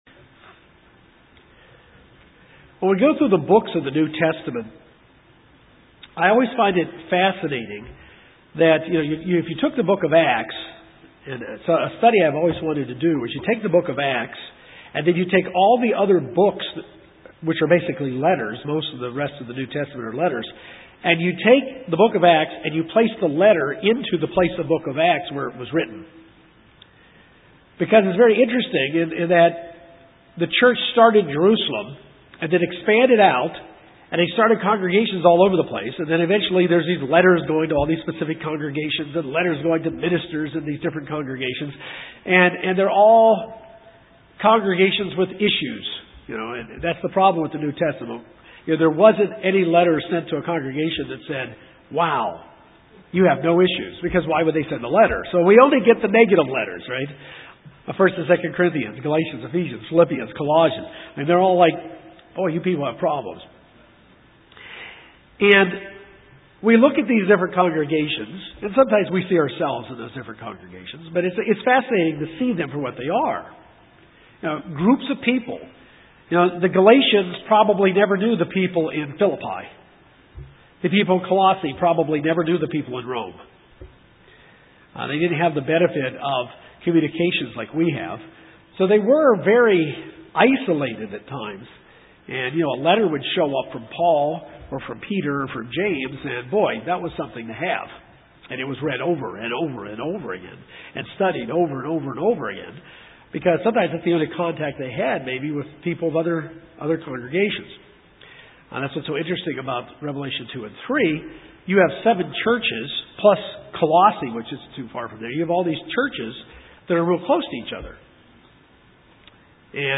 This sermon takes a look at the early church that started on the day of Pentecost to get an understanding of the New Testament model of what God’s church looks like.